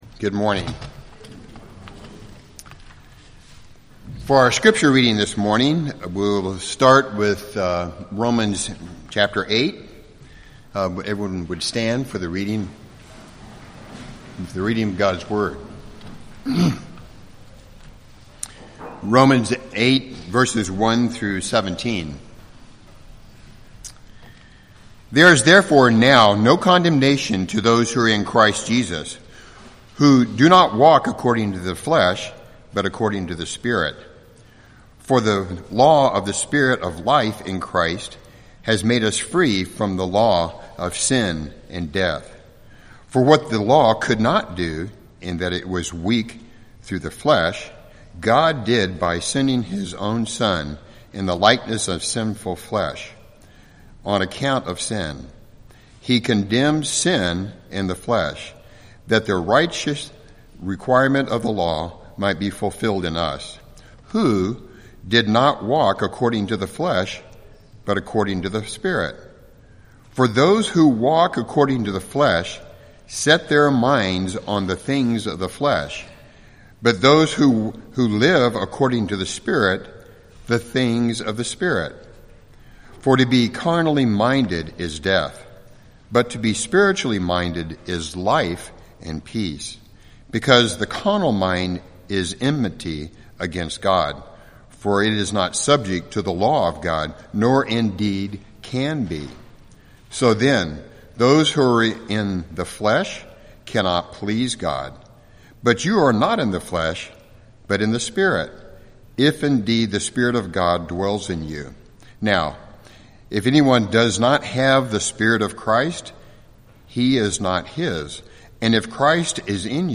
Sermons Jul 05 2021 “Adoption